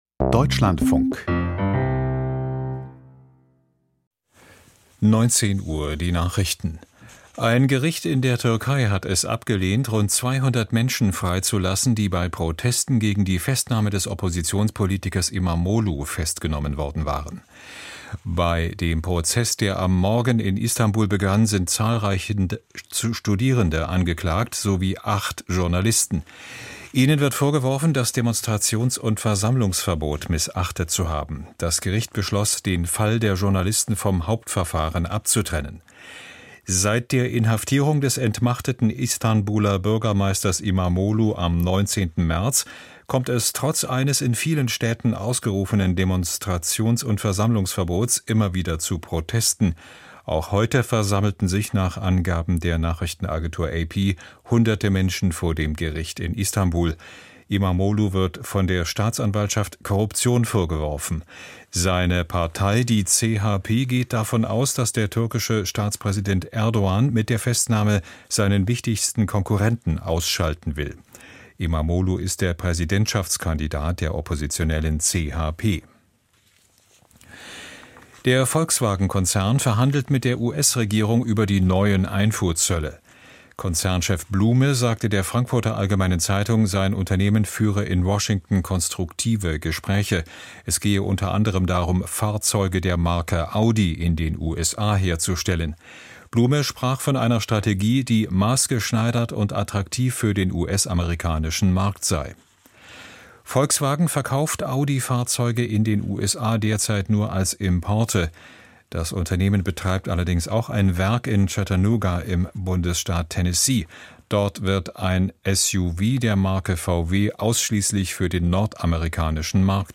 Die Deutschlandfunk-Nachrichten vom 18.04.2025, 19:00 Uhr